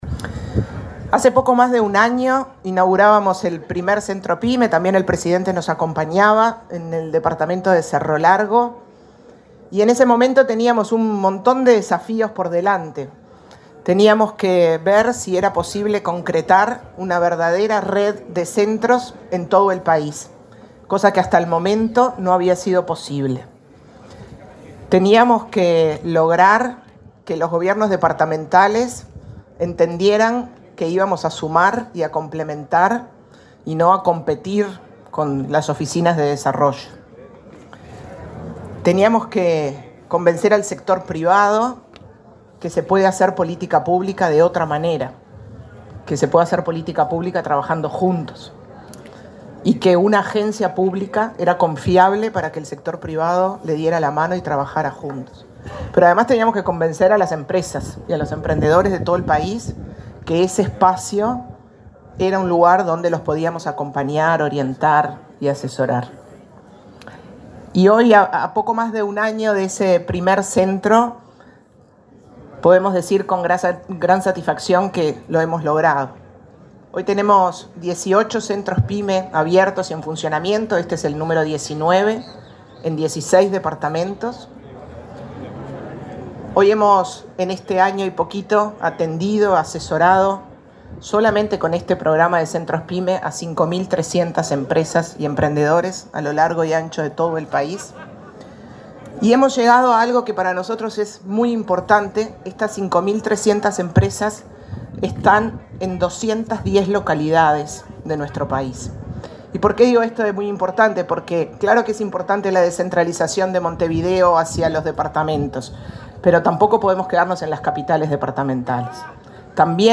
Palabras de la presidenta de ANDE, Carmen Sánchez
La presidenta de la Agencia Nacional de Desarrollo (ANDE), Carmen Sánchez, participó en la inauguración del primer Centro Pyme en Maldonado.